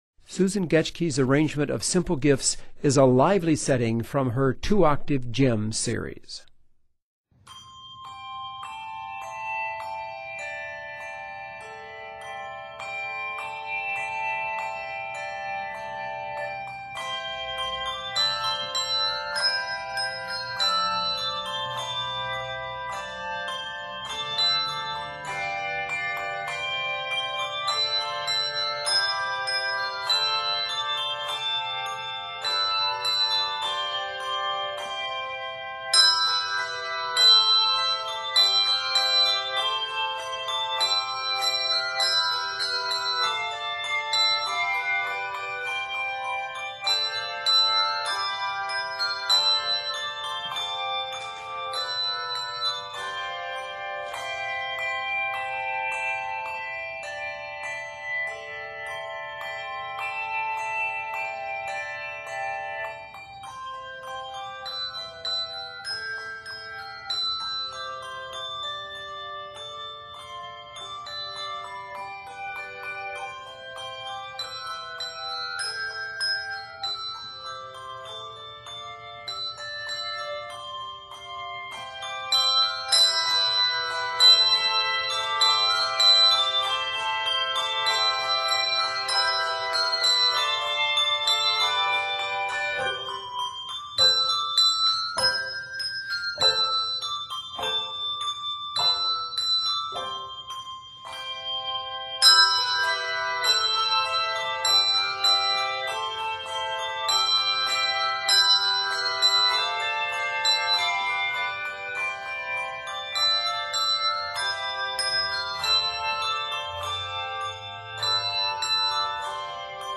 the two octave choir